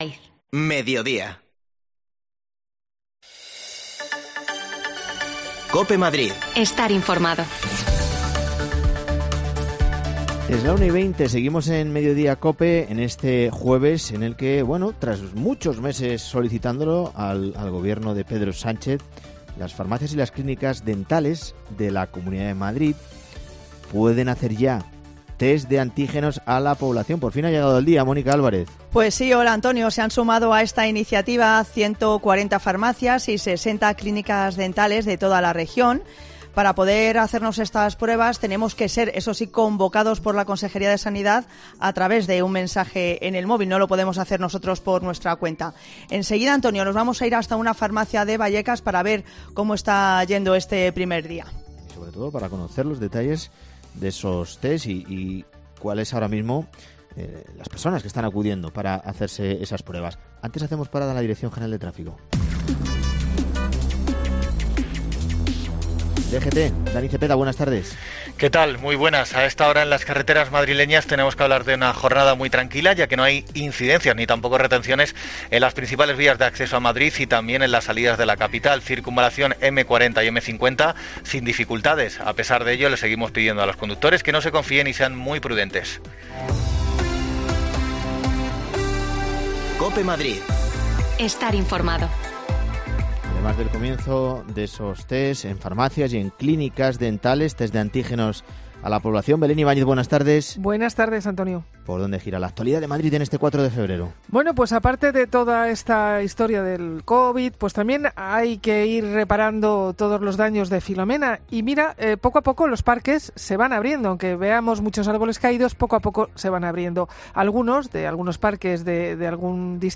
AUDIO: Las farmacias de Madrid han comenzado hoy a hacer test de antígenos a cidadanos asintomáticos. Nos acercamos a una situada en Vallecas
Las desconexiones locales de Madrid son espacios de 10 minutos de duración que se emiten en COPE , de lunes a viernes.